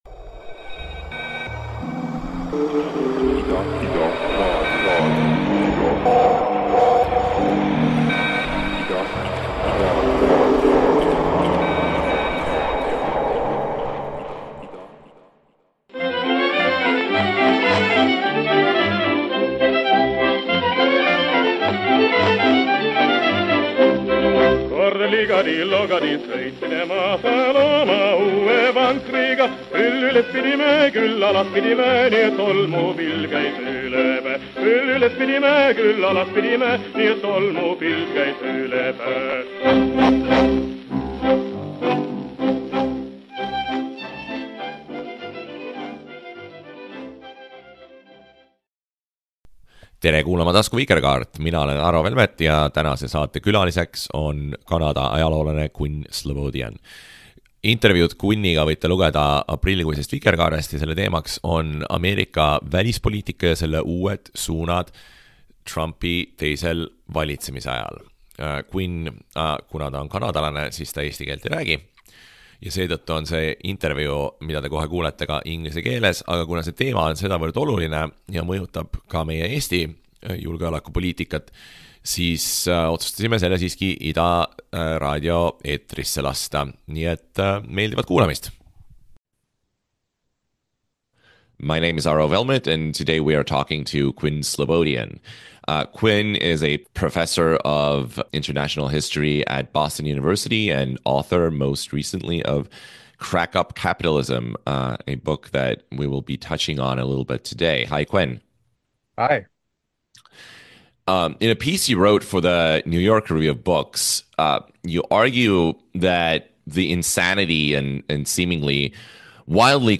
Külaline Quinn Slobodian aitab mõista Trumpi ajastu välispoliitikat: mis diili tehakse Ukrainaga, mis eesmärk on tariifidel ja mille nimel lammutatakse 80 aasta vanune rahvusvaheliste suhete süsteem.